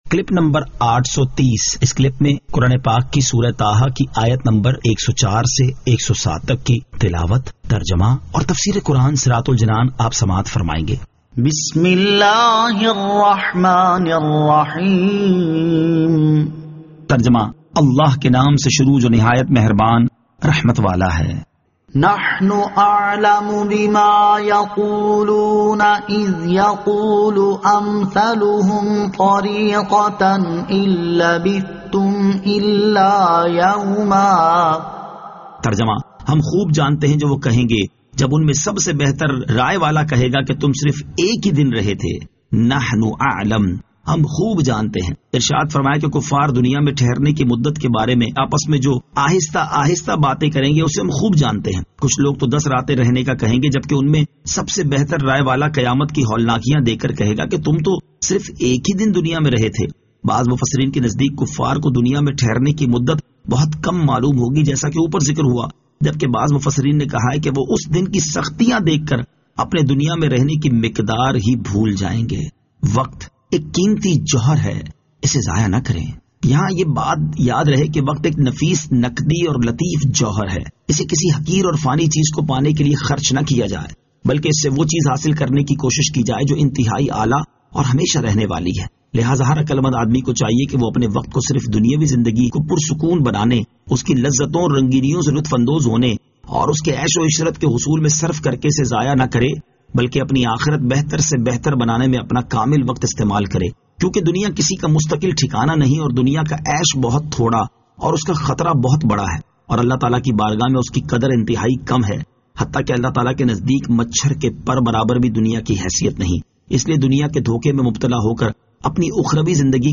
Surah Taha Ayat 104 To 107 Tilawat , Tarjama , Tafseer
2022 MP3 MP4 MP4 Share سُوَّرۃُ طٰہٰ آیت 104 تا 107 تلاوت ، ترجمہ ، تفسیر ۔